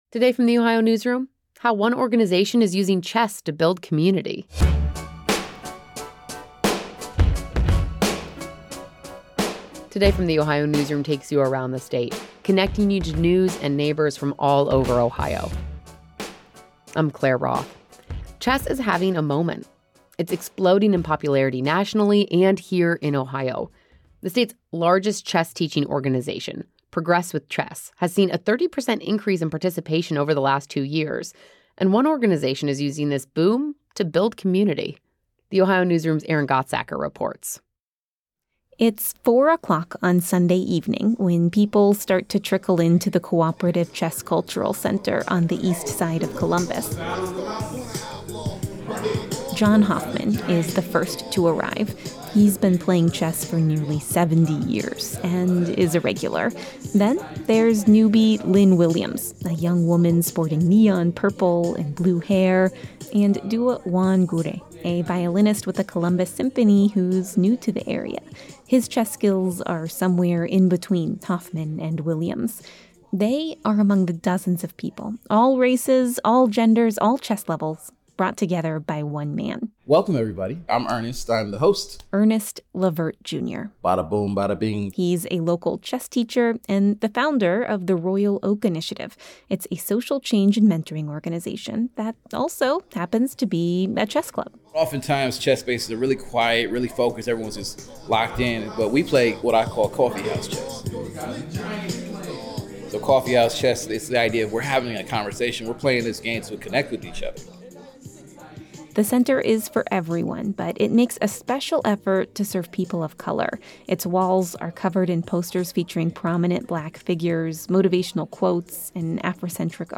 It’s 4 o’clock on Sunday evening when people start to trickle in to the Cooperative Chess Cultural Center on the east side of Columbus.
As the games begin, chatter about chess blends with Tupac music playing from large speakers in the corner of the room.